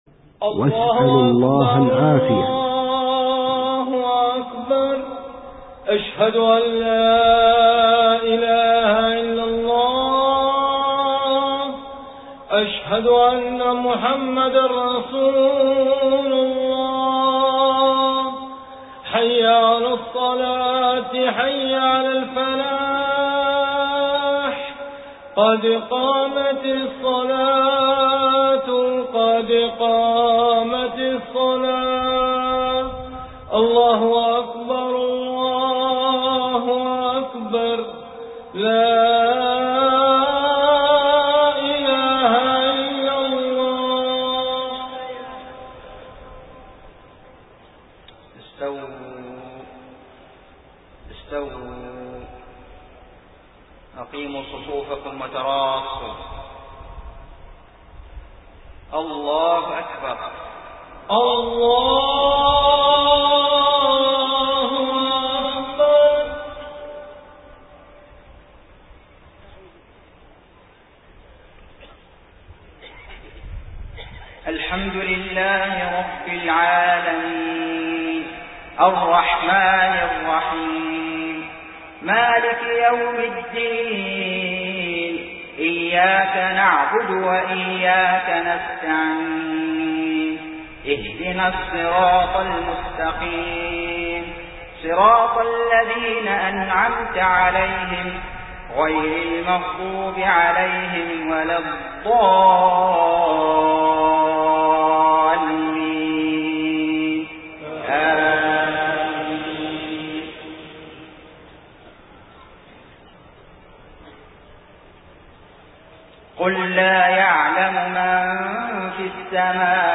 صلاة المغرب 22 محرم 1429هــ من سورة النمل 65-75 > 1429 🕋 > الفروض - تلاوات الحرمين